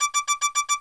warning.wav